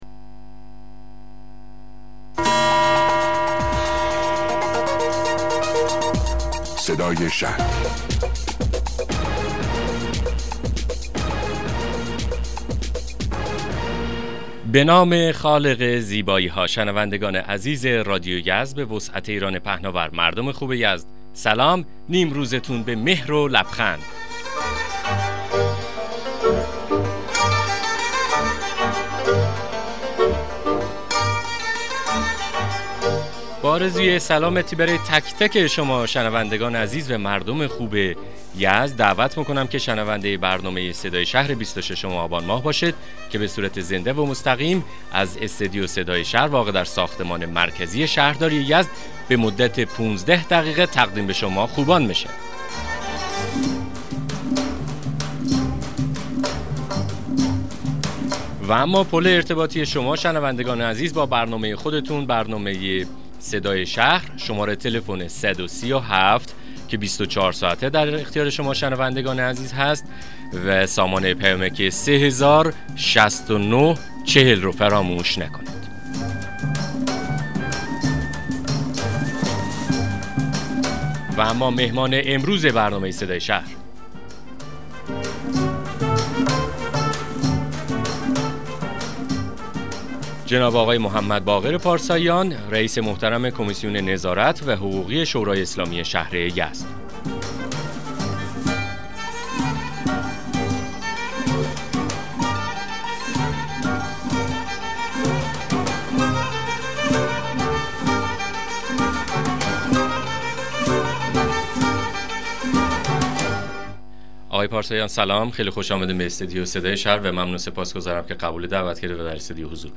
مصاحبه رادیویی برنامه صدای شهر با حضور محمدباقر پارساییان رییس کمیسیون نظارت و حقوقی شورای اسلامی شهر یزد